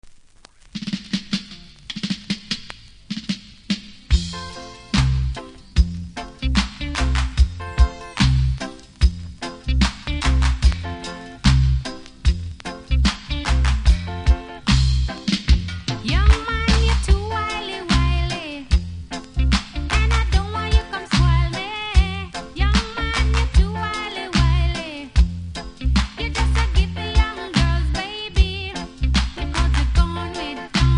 REGGAE 80'S
多少ノイズ感じますがキズも少なく良好盤です。